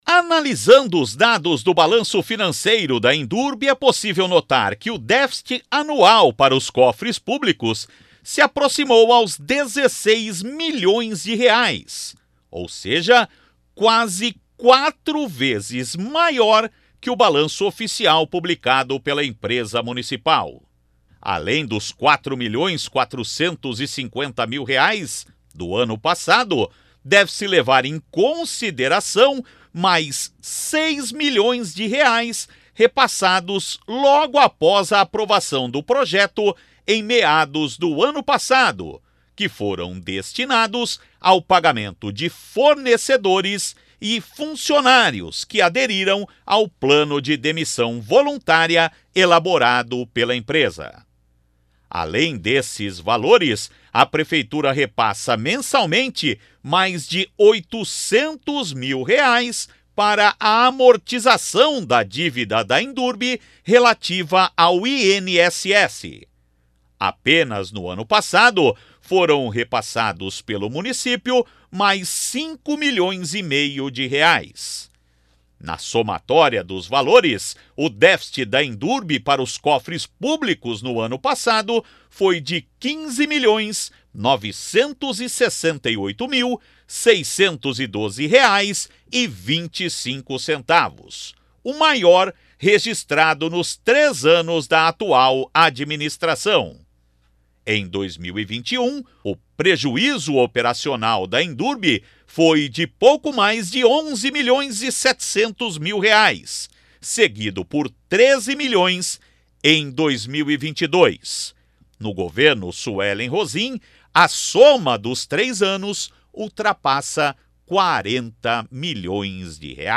Outras informações com o repórter